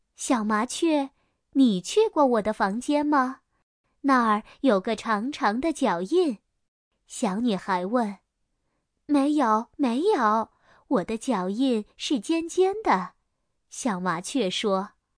点击播放谁的脚印绘本故事音频